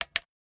Flashlight Turn On.wav